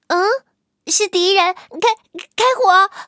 M6夜战攻击语音.OGG